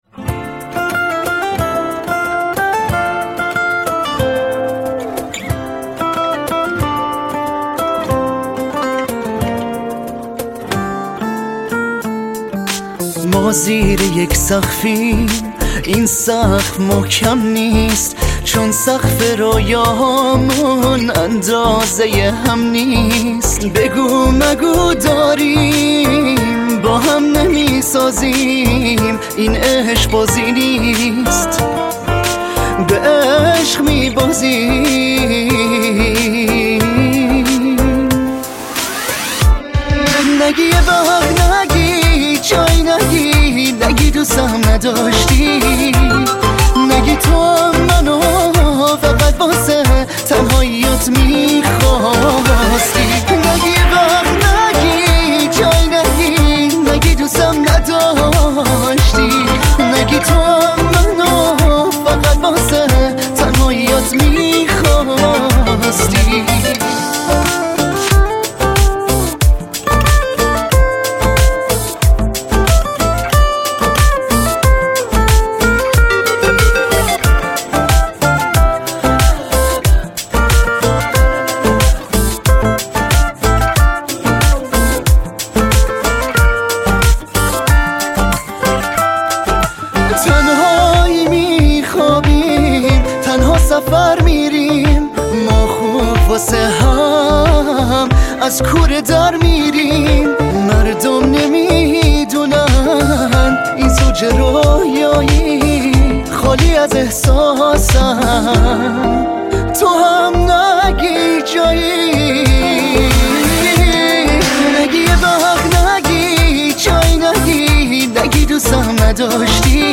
بسیار پر احسلس